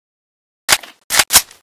ak74_unjam.ogg